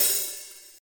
drum-hitfinish.mp3